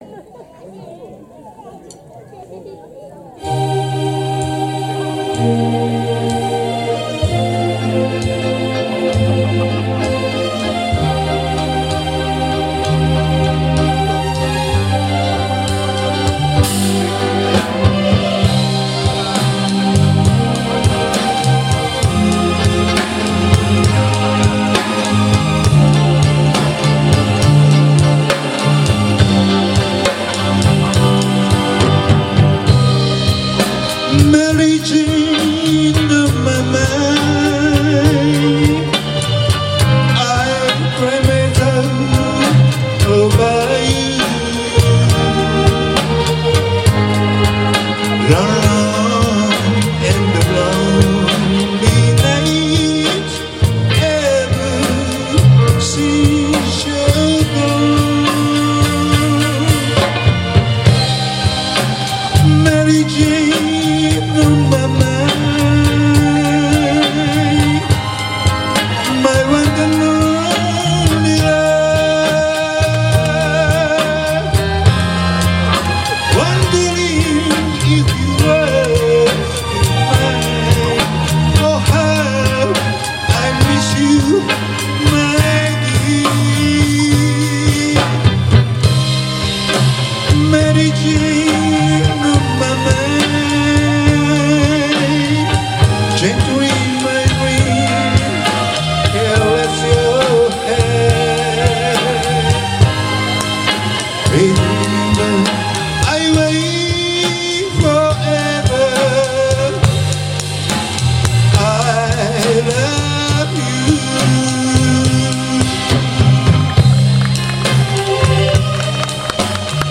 60年代ポップスのホルダーの中に、なぜかオーナーの皆様とのリンゴのお花見の際にオーナー様が主催しているバンドの演奏が混じっていました。
懐かしく聞かせて頂きましたが、ほとんどプロですね ^^